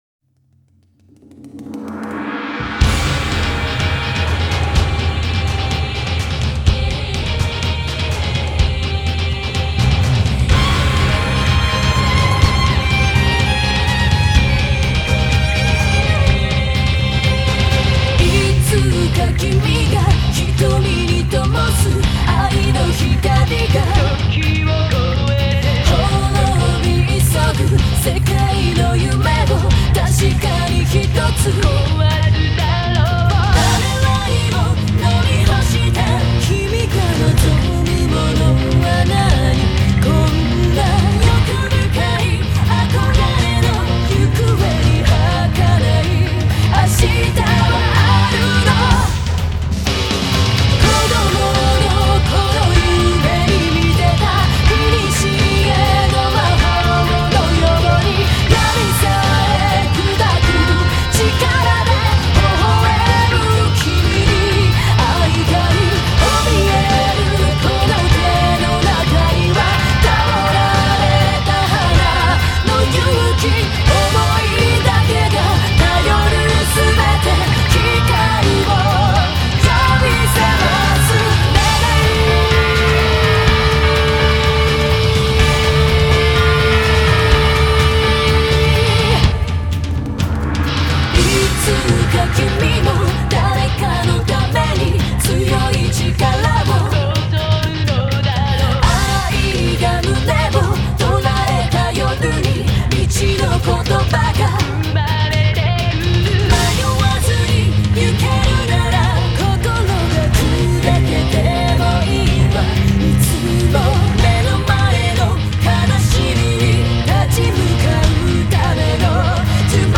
Genre: J-Pop, Female Vocal